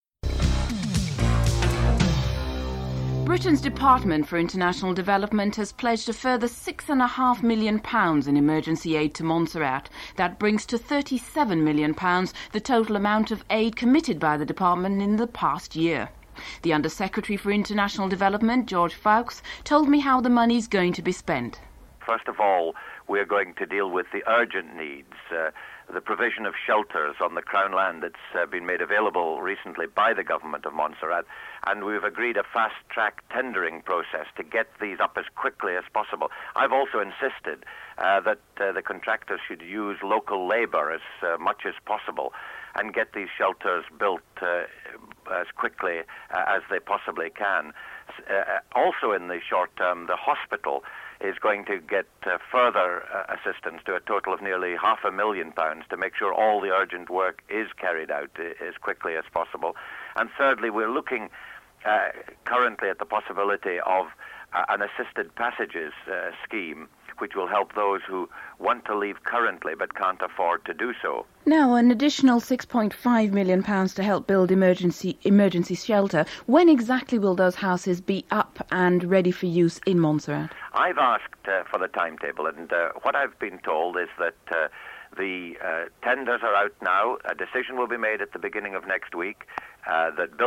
The Under Secretary for International Development in Britain George Foulkes, Prime Minister of Grenada, Keith Mitchell and St. Kitts/Nevis Prime Minister, Denzil Douglas are interviewed (00:00-04:12)en_US
Henry Ford, the Chairman of the Barbados Review Commission is interviewed (09:32-12:14)en_US